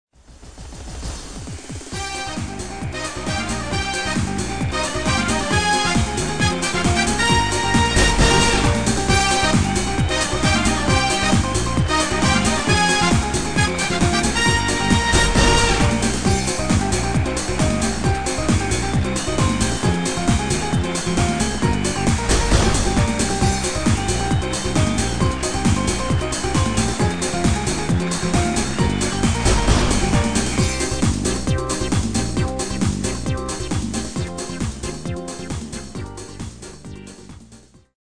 Demo/Koop midifile
Genre: Reggae / Latin / Salsa
- Géén vocal harmony tracks
Demo = Demo midifile